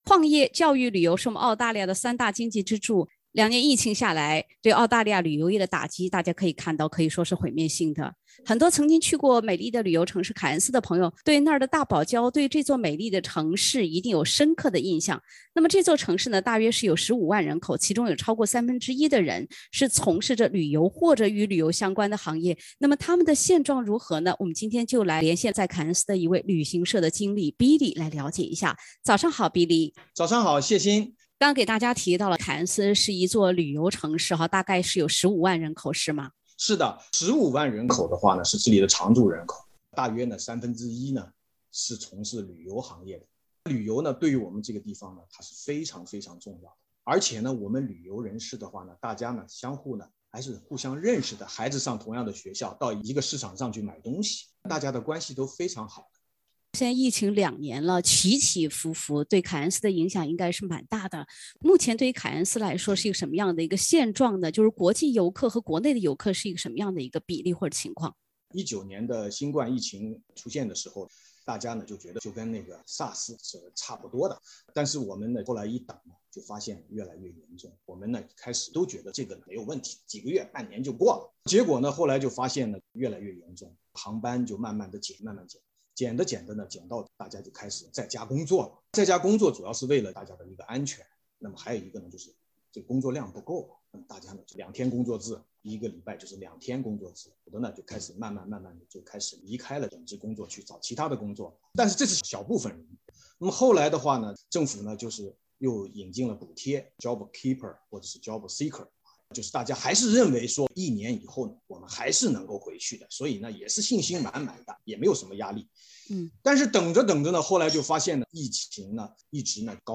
凯恩斯旅游从业人员称，疫情两年多来凯恩斯国际游客几乎为零，国内游客跌去九成。（点击封面图片，收听完整对话）